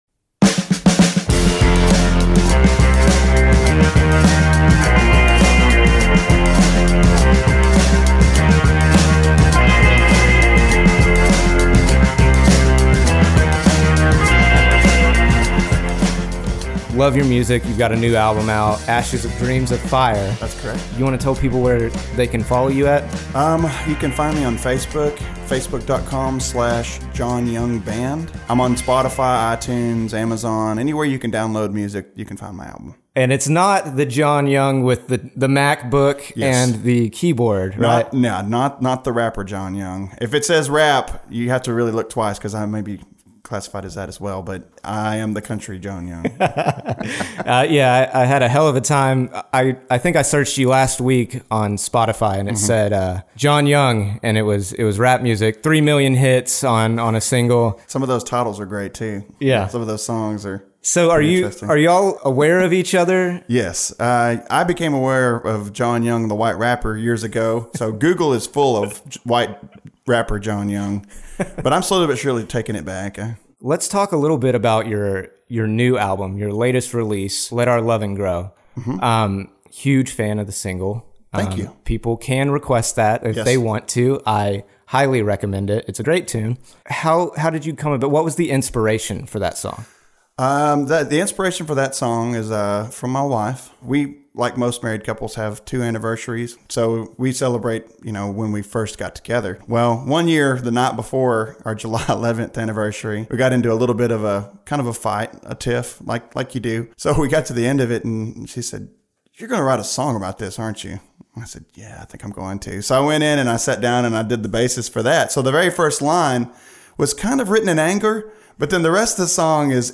Originally aired as part of The Rattle Hour for Rattle Music Magazine in 2016.